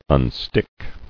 [un·stick]